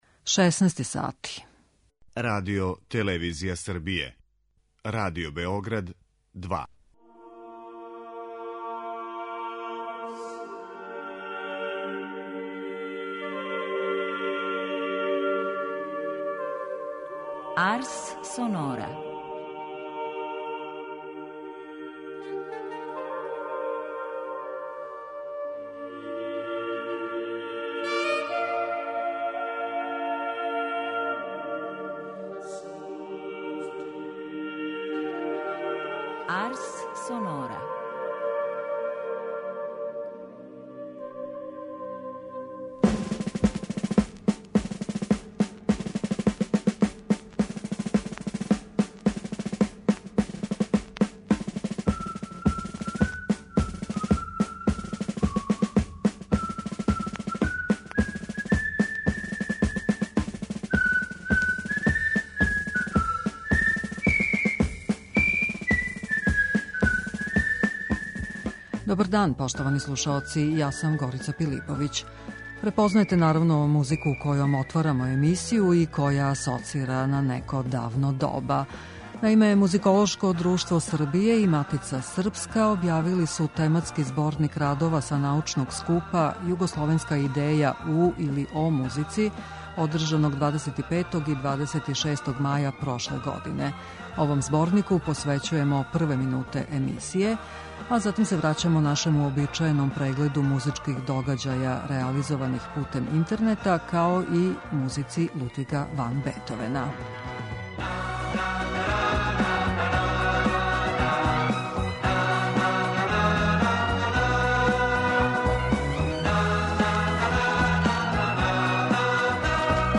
Поводом 250-годишњице рођења овог великог композитора слушаћемо и његов гудачки квартет бр. 6 у извођењу ансамбла Џулијард.